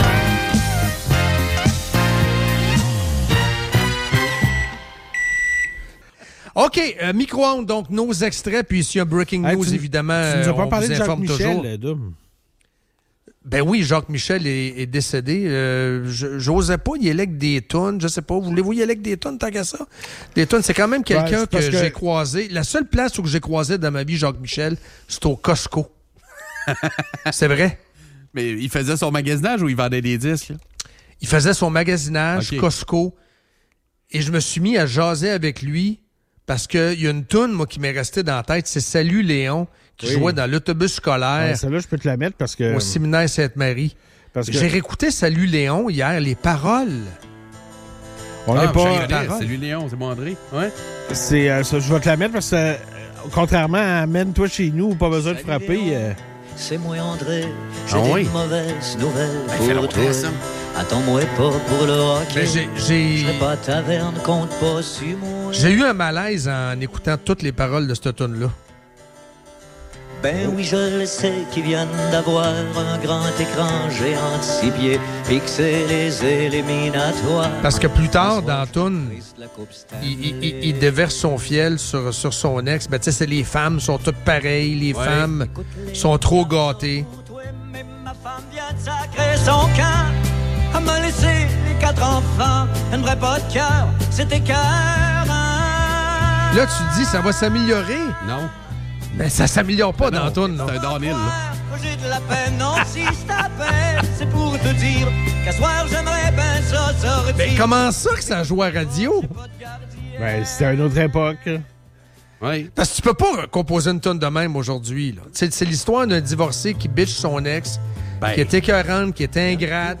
Les animateurs discutent également de la situation du quartier Saint-Roch, des défis économiques et des changements nécessaires pour revitaliser la zone.